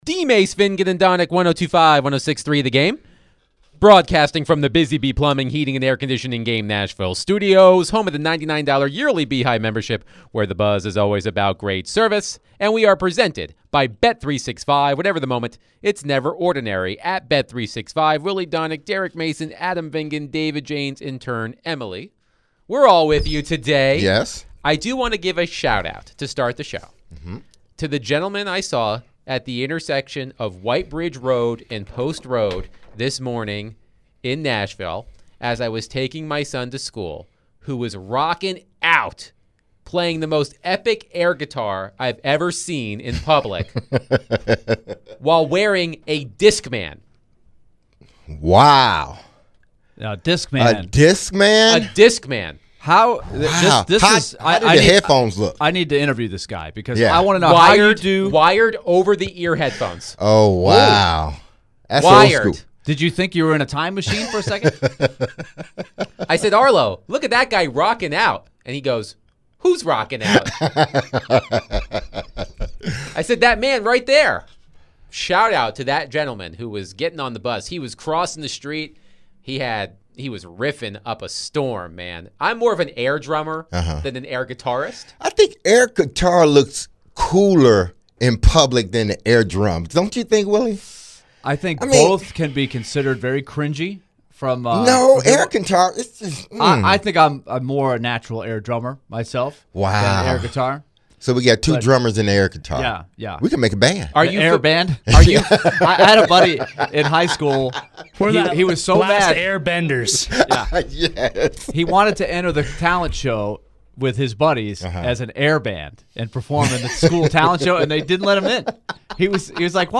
ESPN NFL Analyst Tim Hasselbeck joins DVD to discuss the Titans' off-season, Cam Ward's confidence, the Indy quarterback situation, and more. The guys end the first hour with their thoughts on the interview with Tim Hasselbeck.